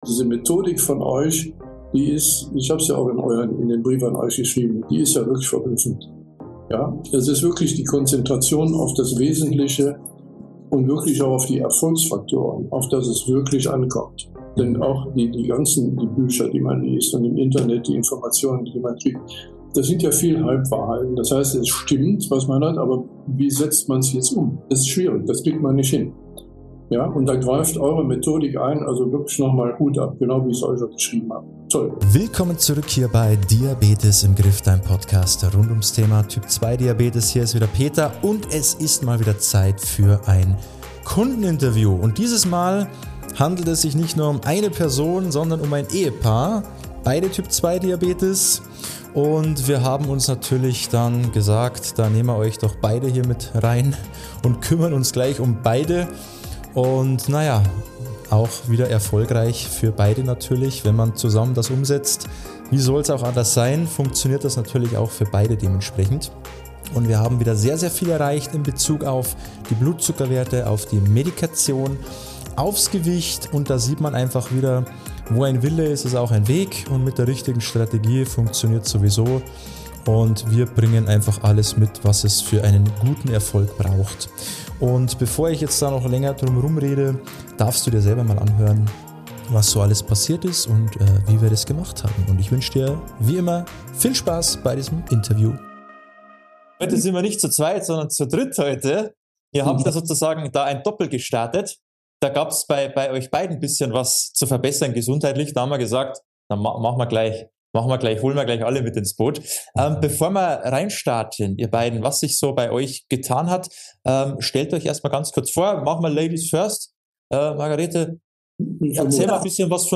spricht mit einem Kundenpaar